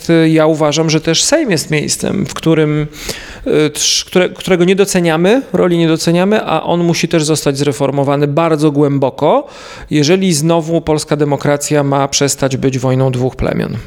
Różnice są rzeczą naturalną, ale parlamenty wymyślono po to, żebyśmy się nie obrzucali kamieniami na ulicach – tak o dużej roli Sejmu mówił niedawno na antenie Twojego Radia Szymon Hołownia – dzisiejszy marszałek.